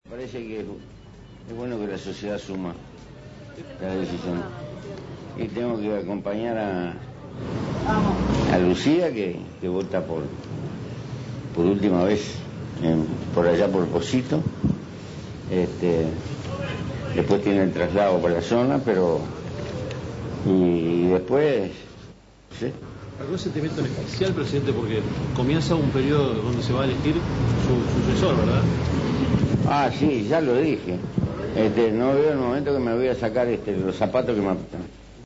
El presidente José Mujica realizó una conferencia de prensa en el Salón de las Artes de la Embajada de Uruguay en Estados Unidos, en la que destacó la importancia del intercambio comercial con ese país.